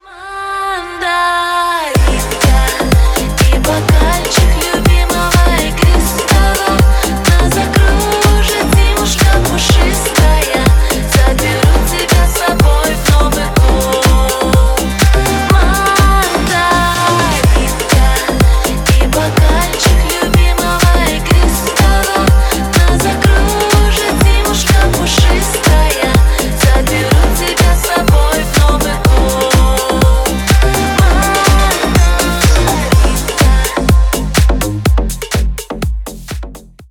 ремиксы
поп